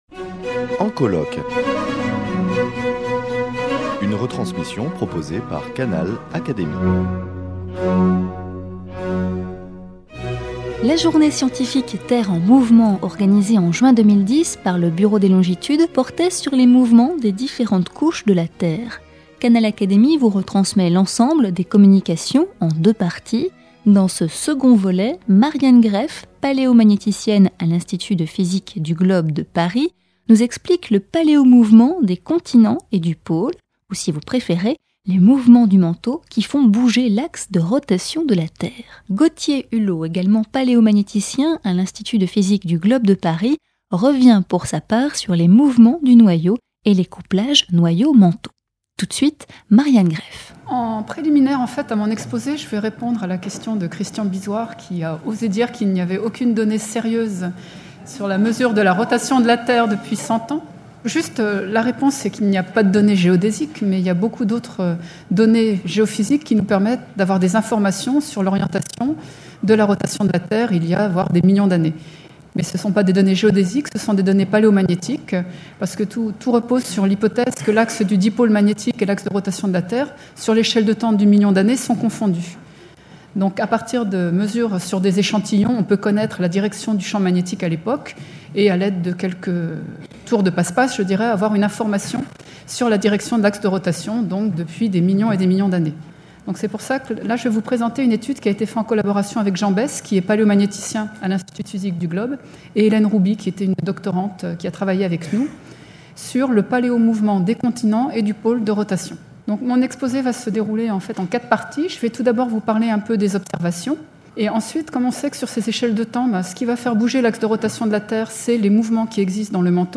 Le colloque "Terre en mouvements" organisé en juin 2010 par le Bureau des longitudes porte sur les différents mouvements des différentes couches de la Terre. Canal Académie vous retransmet l’ensemble des communications en deux parties.